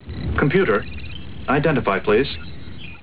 Data saying "Computer, identify please" (24KB) - au